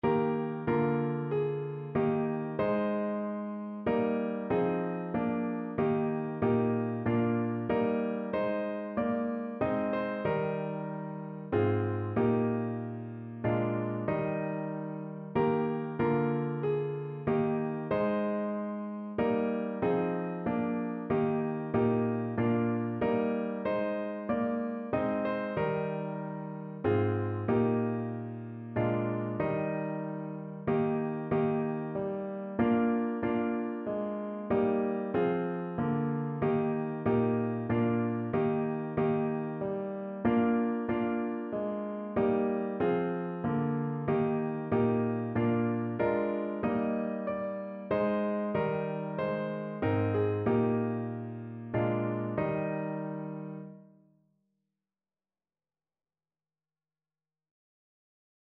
Notensatz 1 (4 Stimmen gemischt)
• gemischter Chor [MP3] 914 KB Download